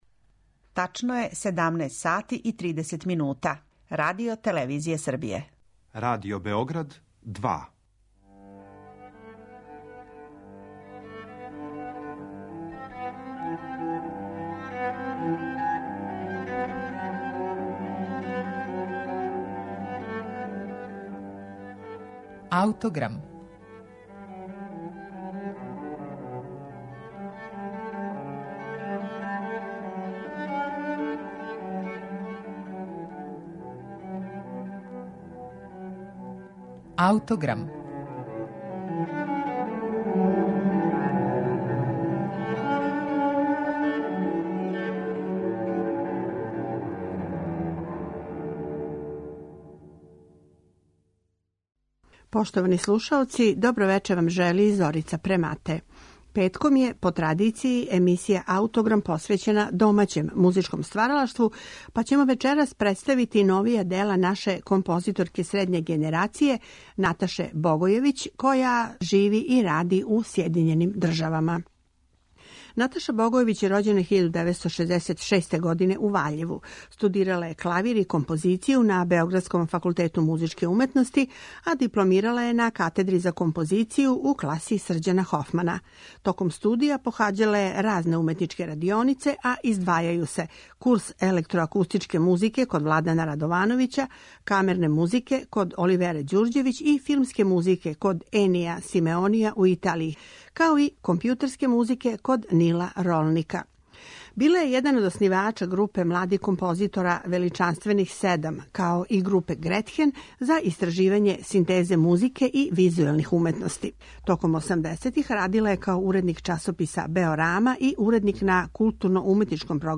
за виолину и виолончело